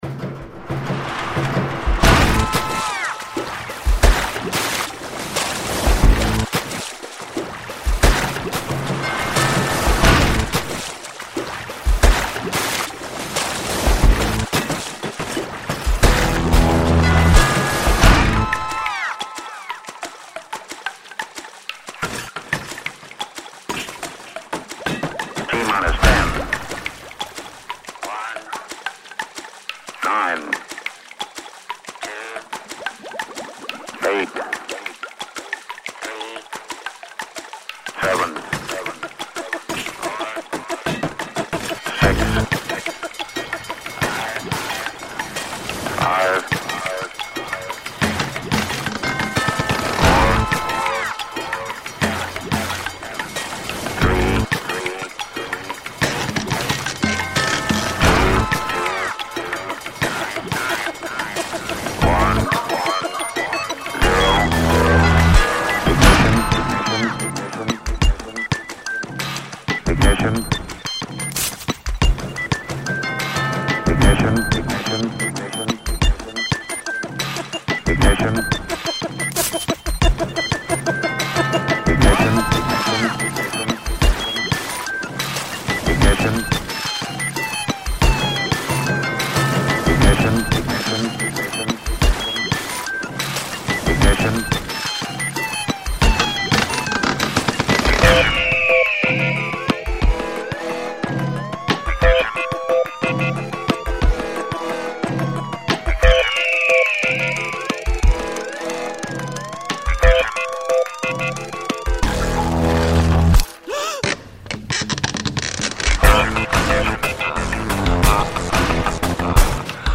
Rationale: Very good musical use of SFX throughout the whole piece.
Created very good use of water sounds for the rhythm bed.  Extremely musical use of the sound effects provided for the contest.